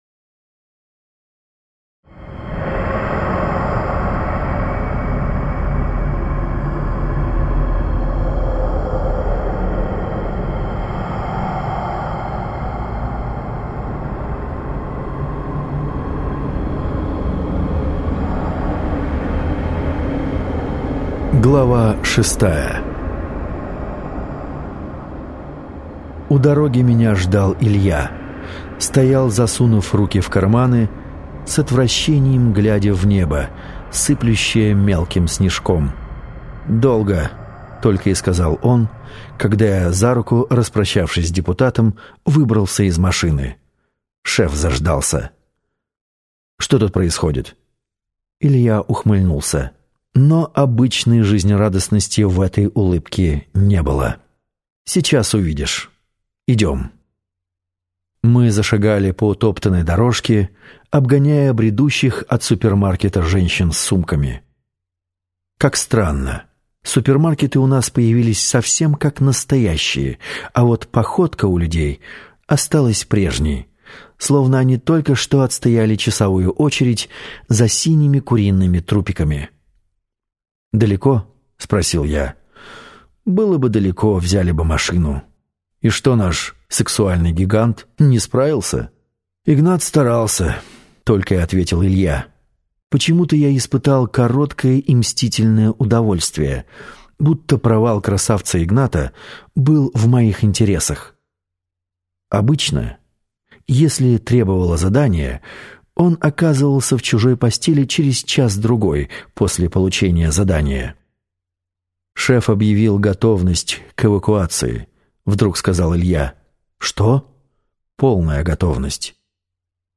Аудиокнига Ночной Дозор - купить, скачать и слушать онлайн | КнигоПоиск